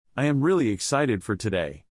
Function words are unstressed when they’re used within a sentence, and vowels in function words generally use the schwa sound.
You’d pronounce these as “uhm” and “fuhr” within a sentence, which isn’t how you’ll find them pronounced in a typical dictionary entry.